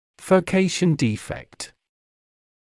furcation defect [fɜː'keɪʃn 'diːfekt] [dɪ'fekt]